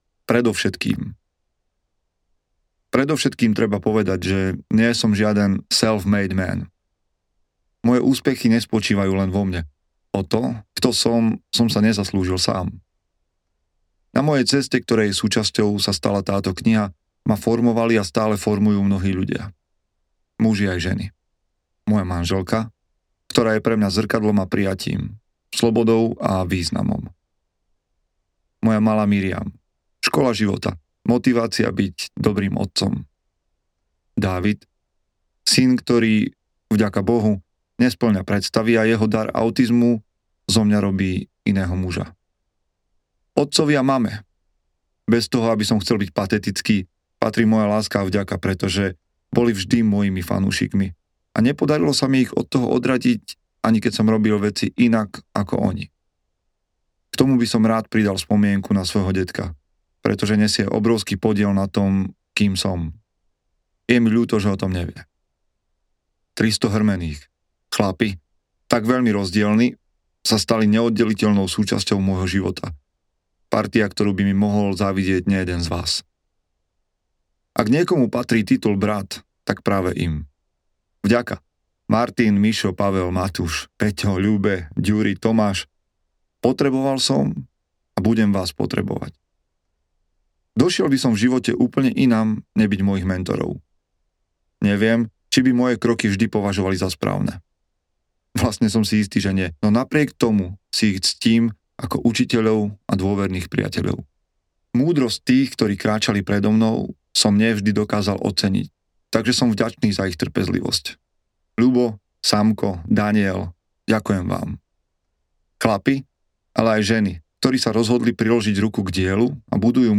Odovzdávanie ohňa audiokniha
Ukázka z knihy